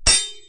default_sword_steel2.ogg